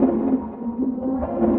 Index of /musicradar/rhythmic-inspiration-samples/150bpm